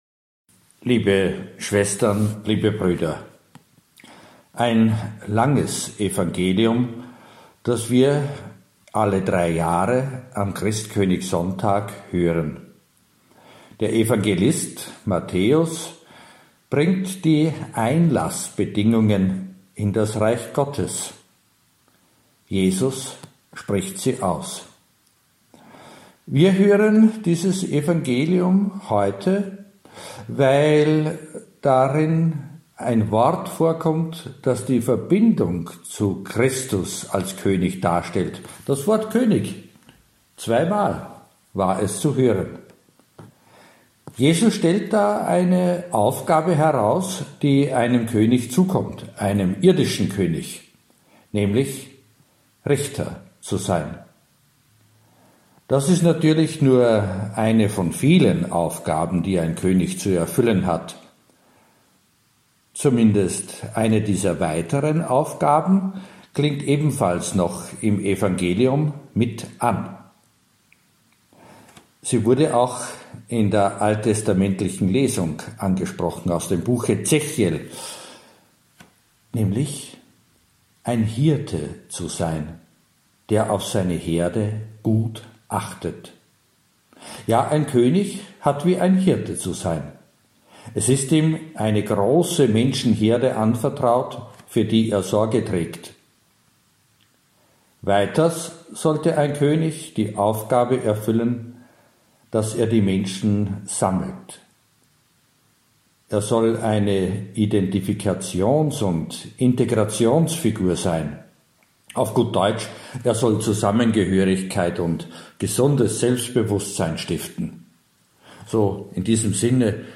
* Predigt zum Anhören